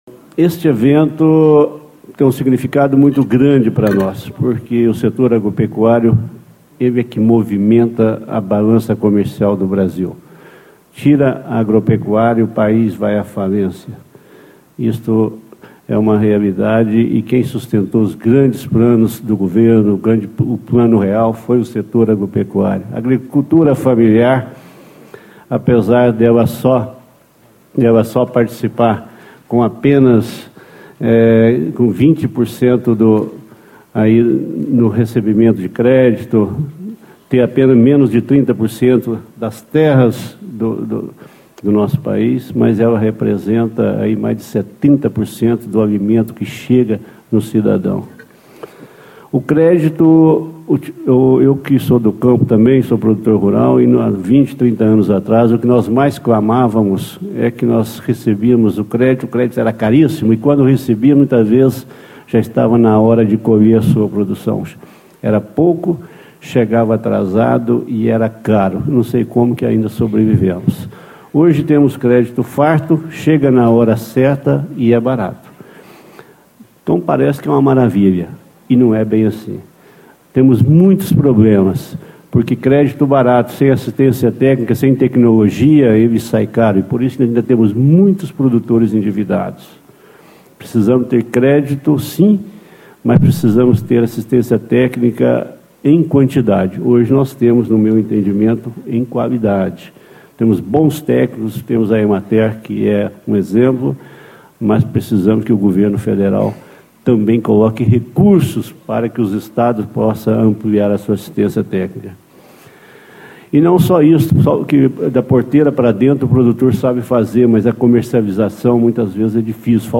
Deputado Antônio Carlos Arantes (PSC), Presidente da Comissão de Política Agropecuária e Agroindustrial
Discursos e Palestras